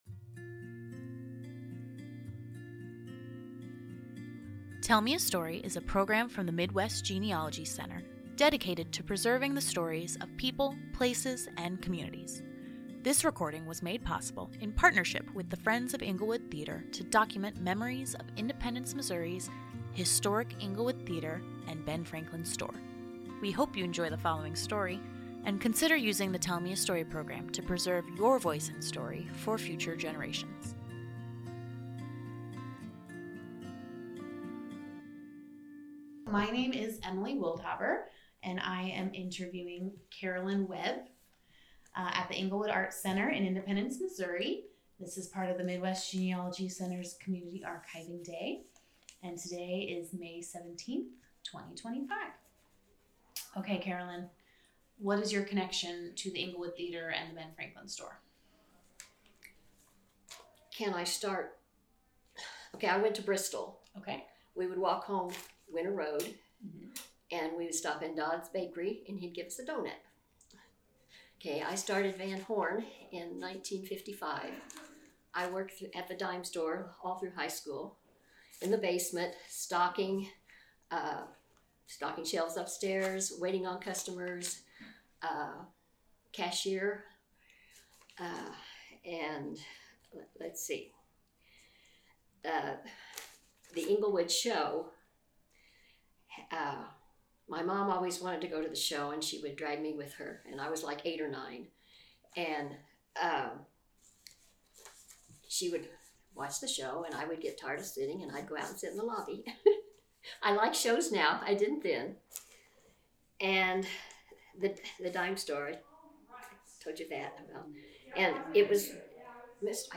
Englewood Theater Community Archiving Day - Oral Histories
Oral History